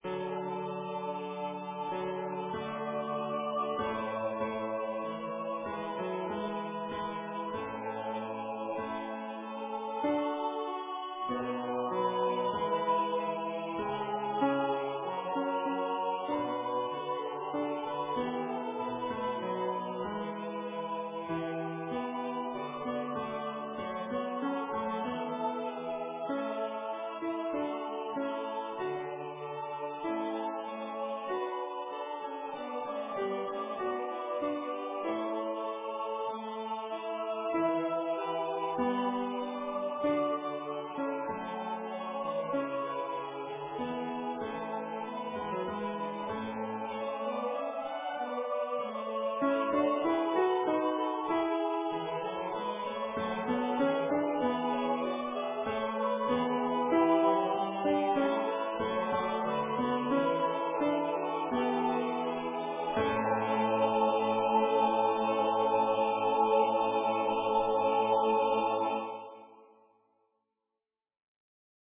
Number of voices: 4vv   Voicing: SATB
Genre: SacredOffertory for Corpus Christi
Instruments: A cappella